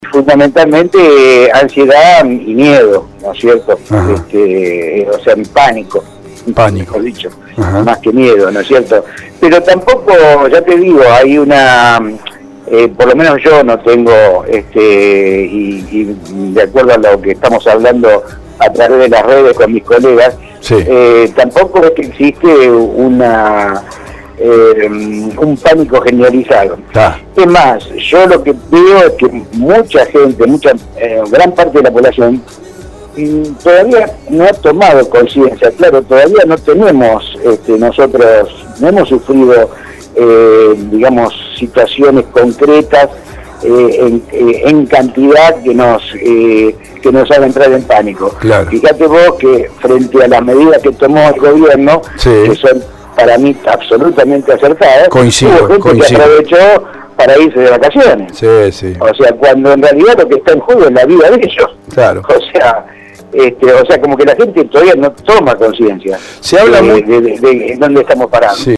Interesante entrevista de este martes